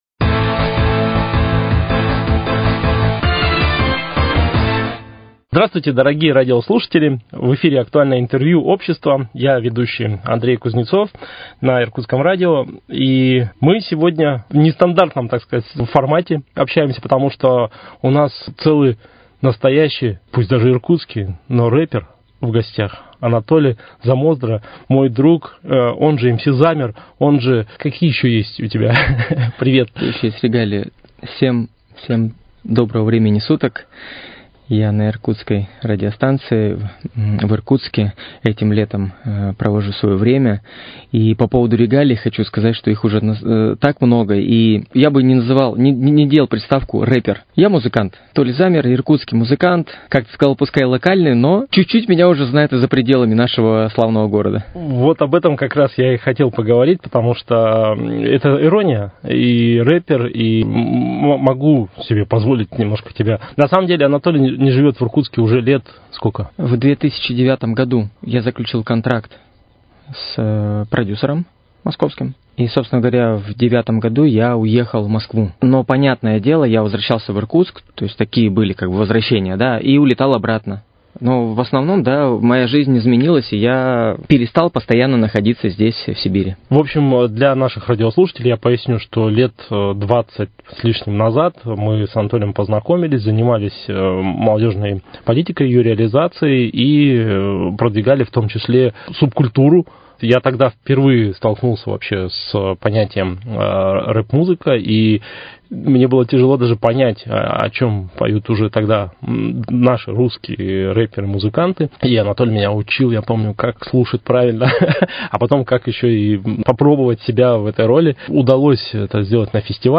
Актуальное интервью: Беседа с музыкантом